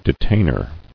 [de·tain·er]